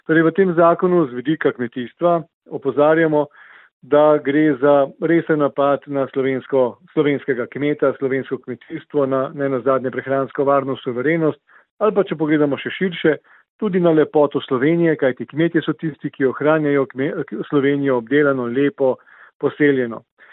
izjava Podgorsek 1 za splet.mp3